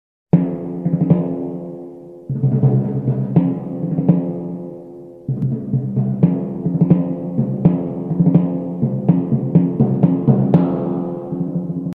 SONIDOS_DETERMINADOS_-_TIMBALES__musica.mp3